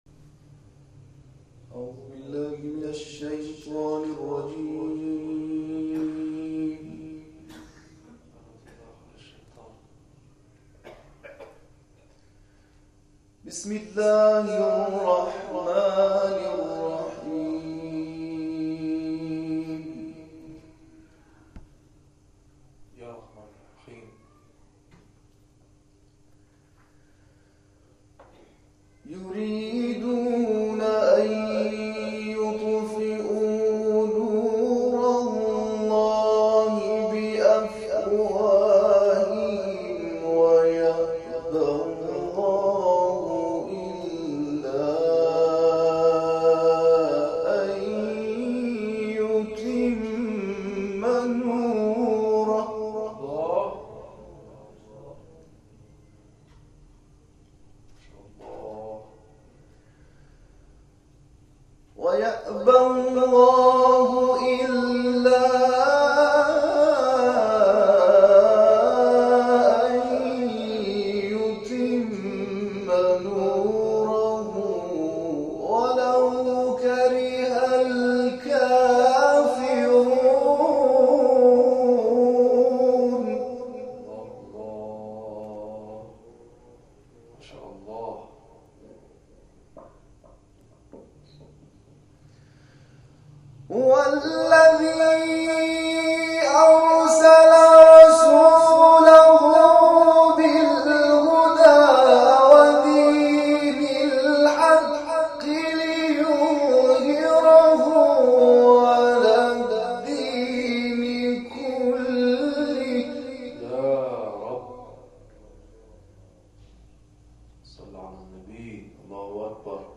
قاری جوان ممتاز که به تقلید از استاد عبدالعزیز حصان در این جلسه تلاوت کرد